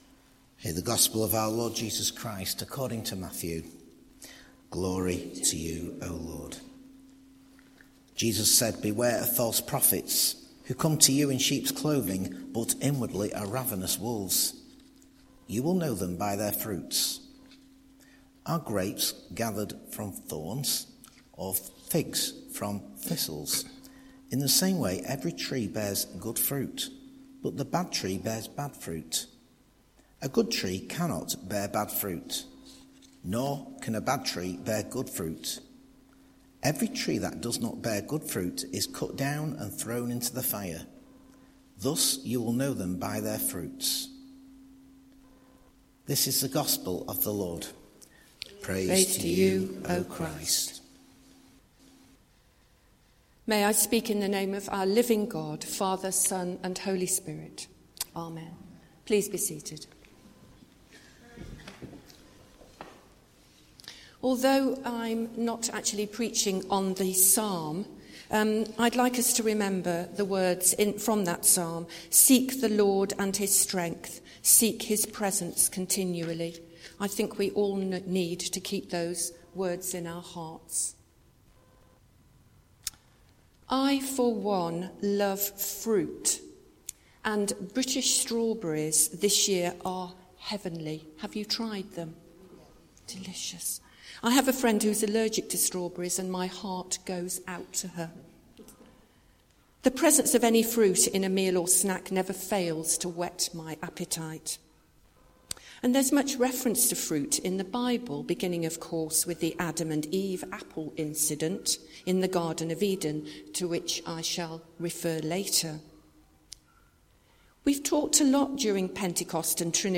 A sermon for HOLY CROSS DAY